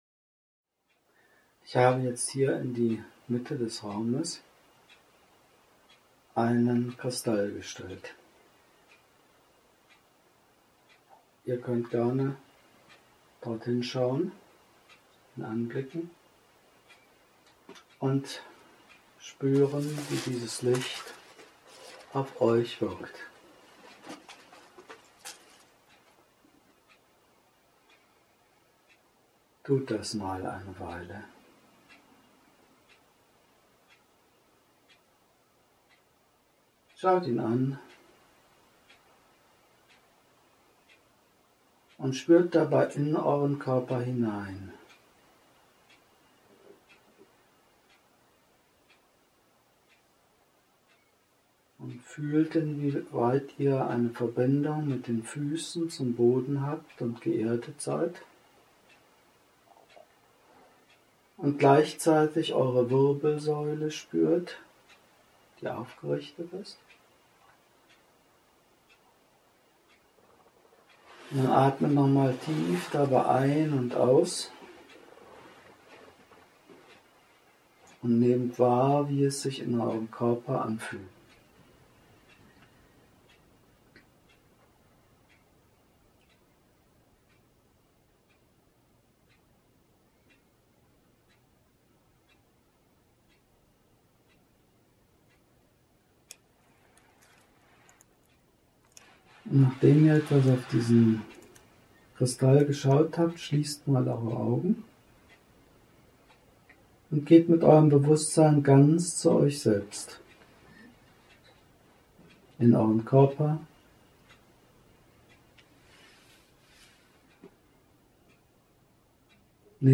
Diese Meditation ist live aufgenommen worden in einem Seminar